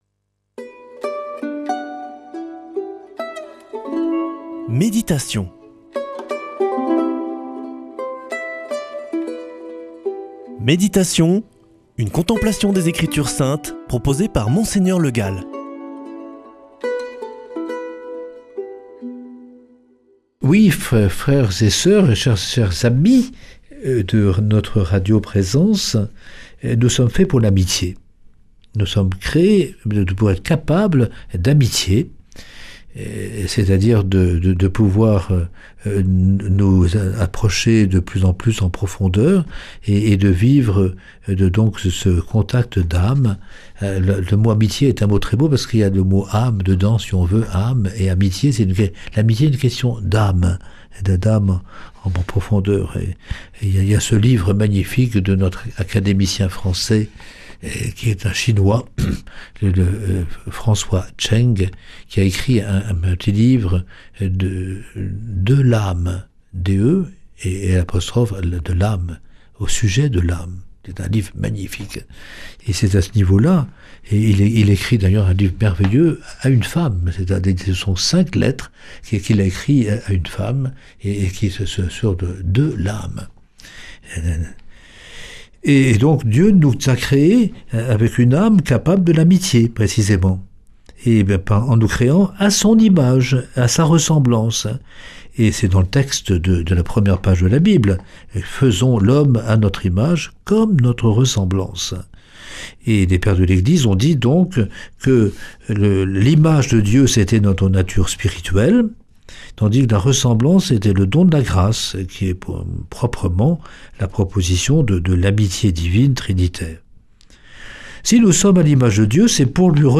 Méditation avec Mgr Le Gall
Présentateur